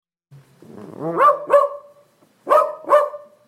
dachshund-short.mp3